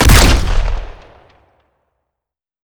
generic rifle
fire3.wav